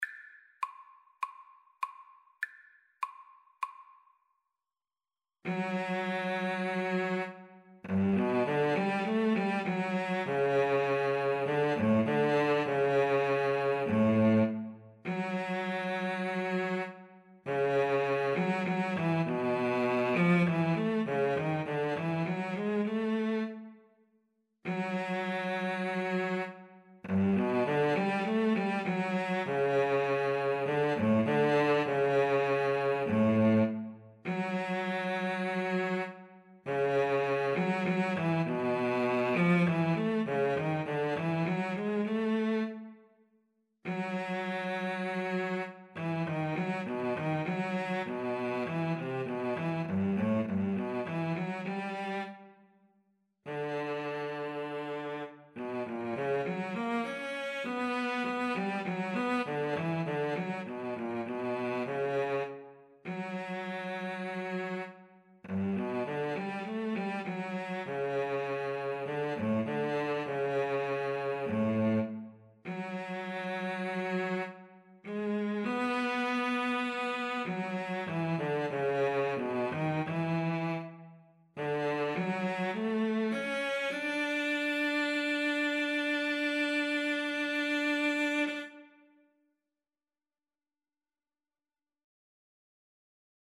4/4 (View more 4/4 Music)
Classical (View more Classical Violin-Cello Duet Music)